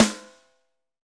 Index of /90_sSampleCDs/Roland L-CD701/SNR_Rim & Stick/SNR_Rim Modules
SNR RINGER06.wav